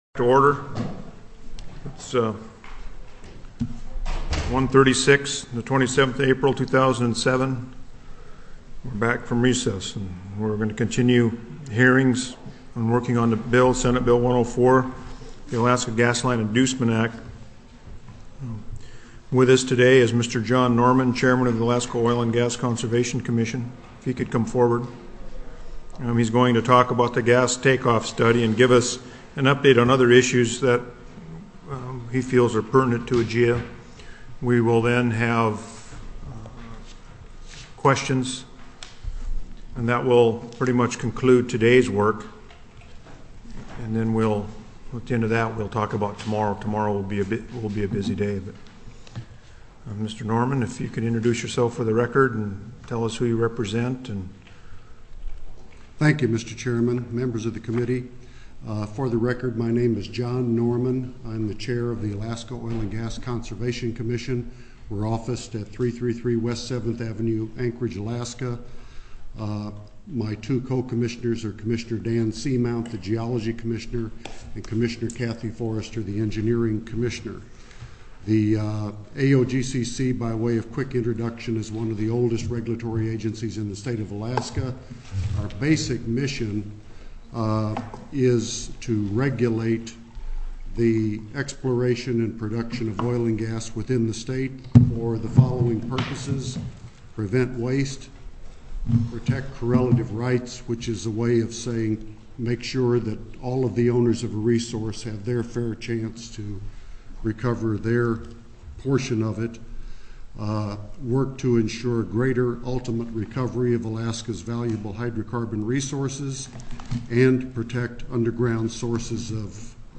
The Committee heard testimony from the Alaska Oil & Gas Conservation Commission.